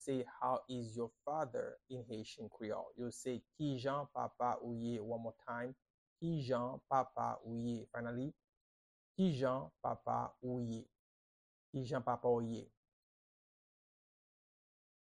How-is-your-father-in-Haitian-Creole-–-Kijan-papa-ou-ye-pronunciation-by-a-Haitian-teacher.mp3